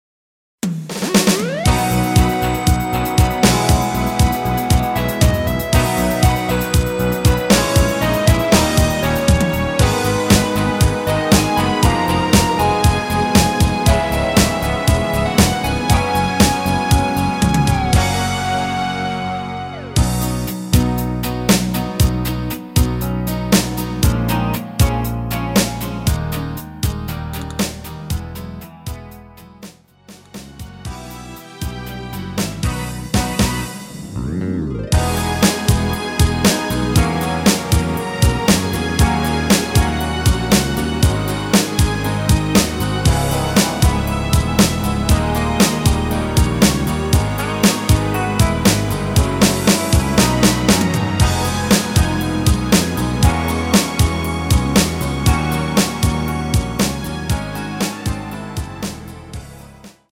-3)내린 MR 입니다.